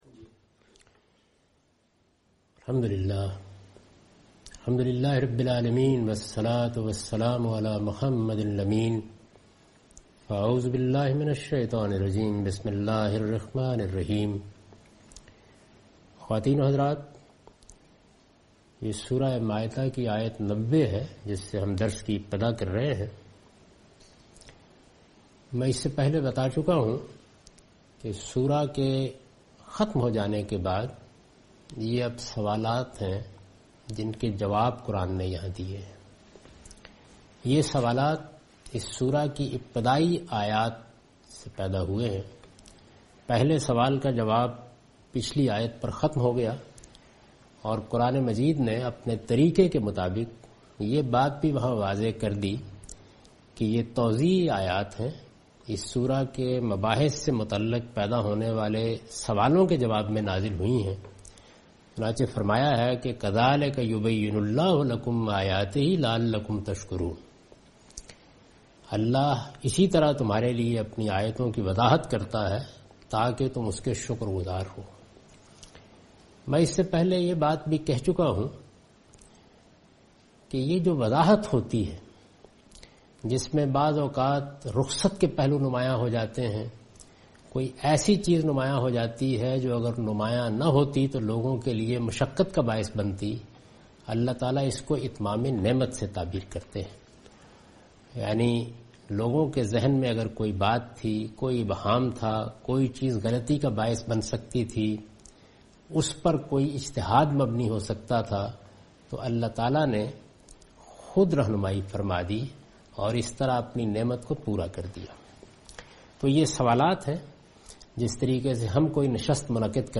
Surah Al-Maidah - A lecture of Tafseer-ul-Quran – Al-Bayan by Javed Ahmad Ghamidi. Commentary and explanation of verse 90 and 93